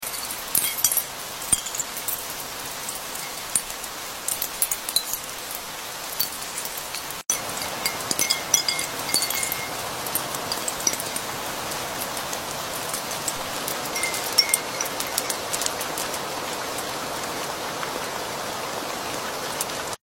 Glass Rain ASMR 🌧 | sound effects free download